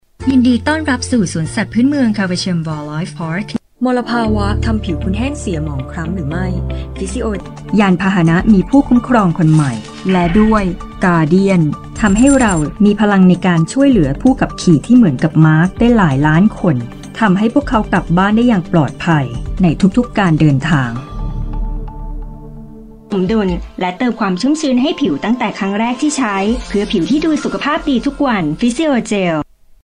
女泰语04 泰语女声 广告讲述MG动画全能 沉稳|积极向上|亲切甜美|素人
女泰语04 泰语女声 干音全能 沉稳|积极向上|亲切甜美|素人